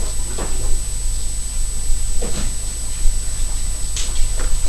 HMC 660 X mit Millenium PP2B fiept enorm
Sobald ich aber in meiner DAW (Studio One) auf Aufnahme drücke, kommt ein wirklich schlimmes Fiepen in die Ohren und auch in die Aufnahme.
Hier eine (verstärkte) Version davon.